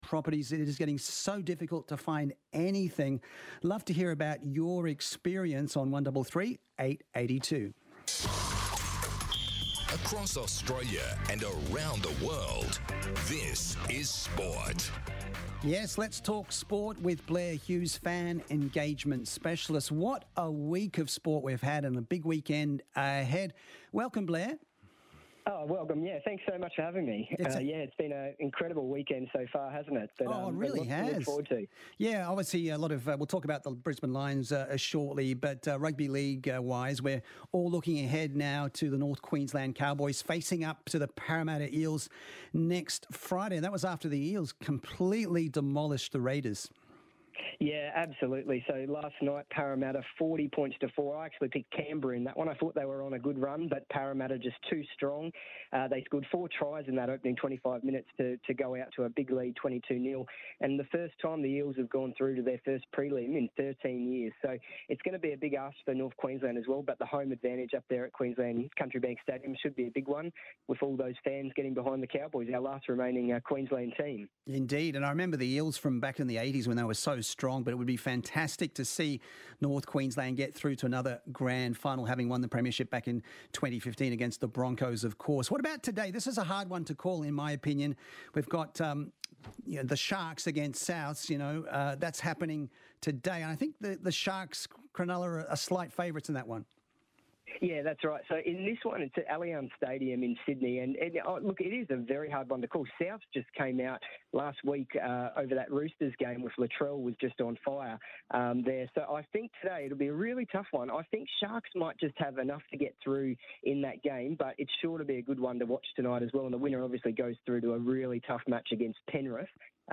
4BC SPORTS REPORT 17.9.22